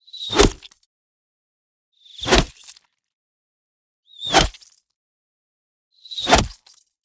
SFX弓箭头射在肉上音效下载
SFX音效